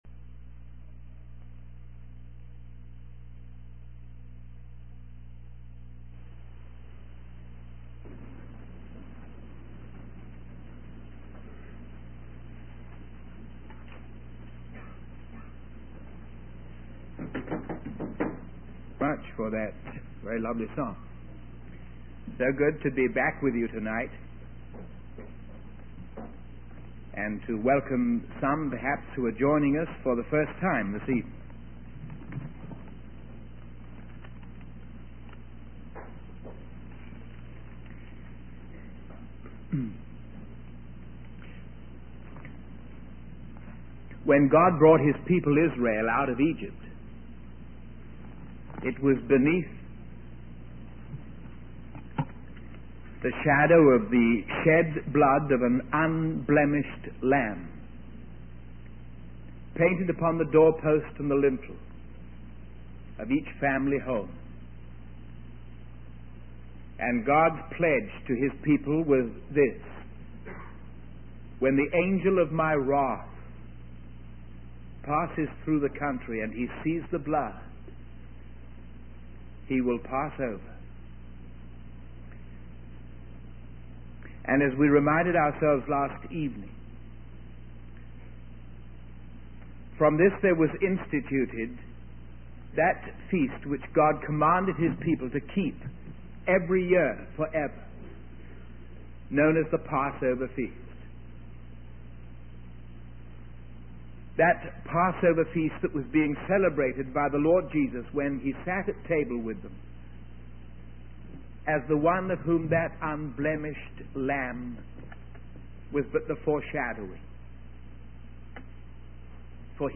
In this sermon, the preacher discusses the concept of redemption and its purpose in bringing about a radical change of character. He refers to the Old Testament and how the Israelites, even after being redeemed from Egypt, still pleased themselves and did what they considered right in their own eyes while in the wilderness.